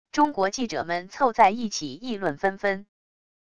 中国记者们凑在一起议论纷纷wav音频